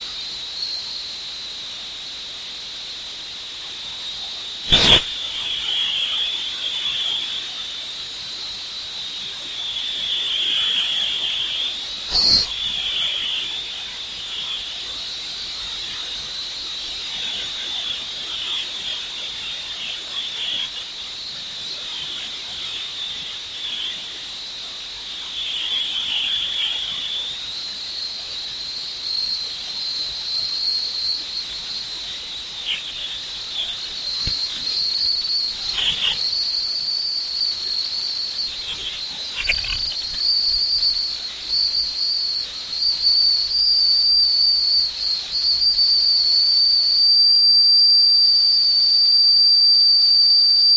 ultrasound subliminal projection into air using an ultrasound speaker. With normal hearing the subliminal is unheard. Recorded with ultrasound microphone.
ultrasound signal modulated by TV
29-Aug-ultrasound-signal-modulated-through-TV.mp3